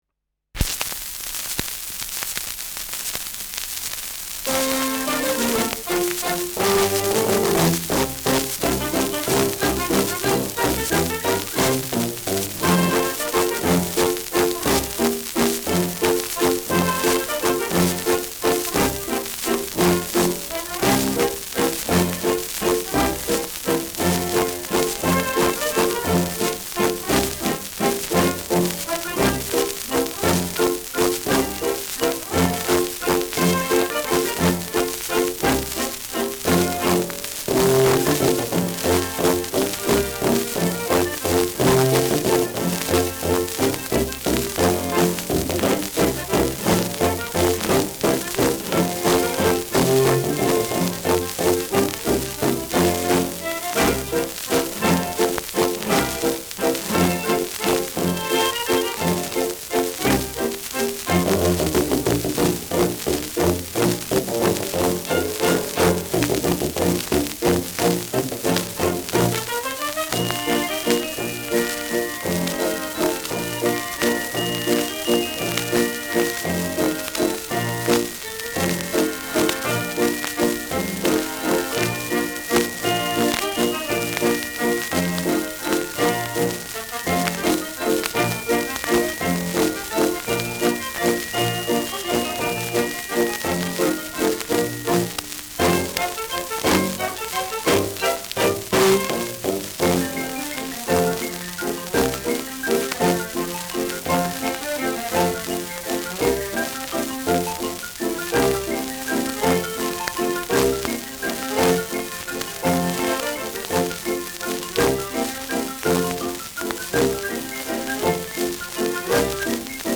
Schellackplatte
[Camden] (Aufnahmeort)